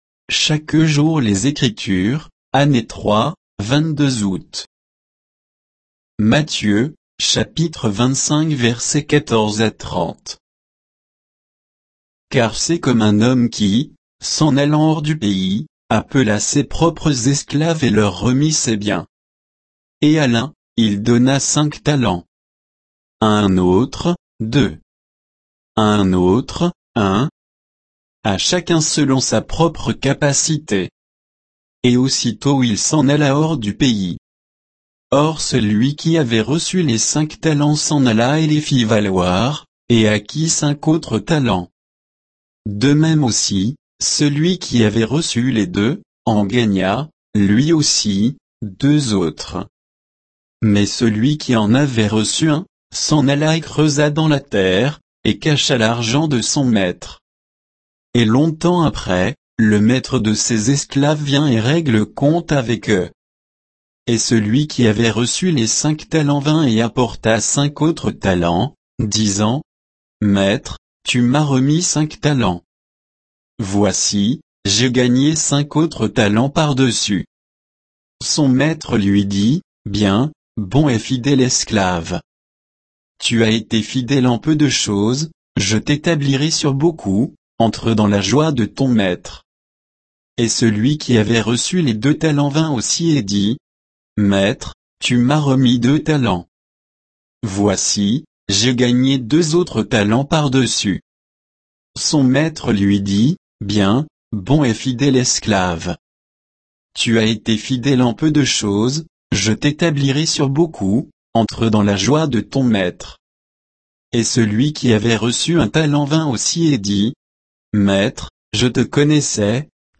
Méditation quoditienne de Chaque jour les Écritures sur Matthieu 25